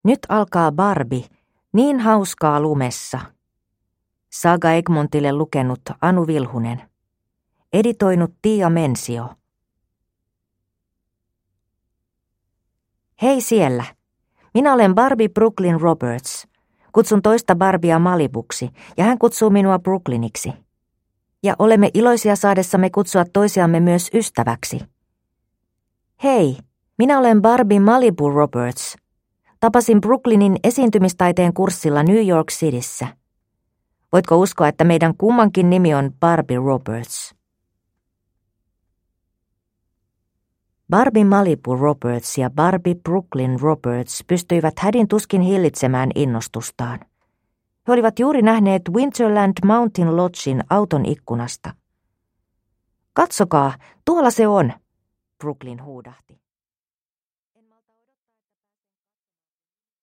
Barbie: Niin hauskaa lumessa! – Ljudbok